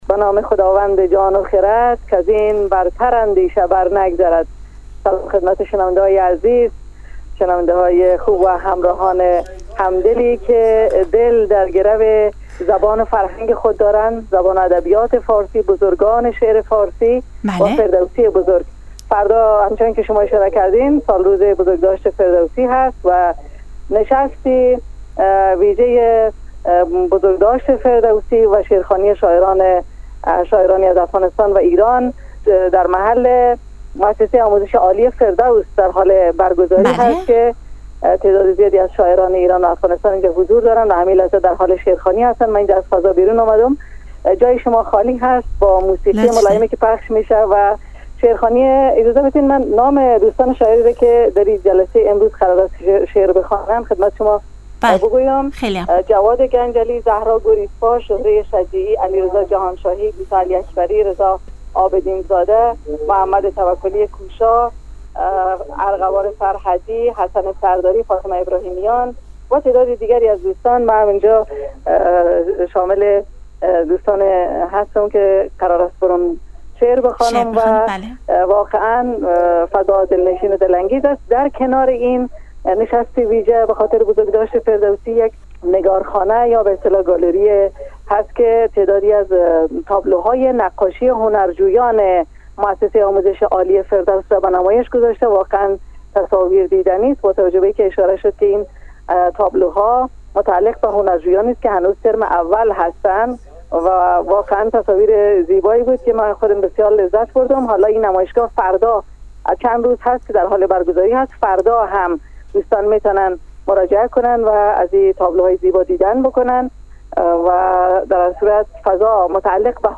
شعرخوانی شاعران ایرانی و افغانستانی در محفل گرامیداشت فردوسی
به همت مؤسسه آموزش عالی فردوس محفل گرامیداشت حکیم ابوالقاسم فردوسی با حضور شاعران ایرانی و افغانستانی برگزار شد.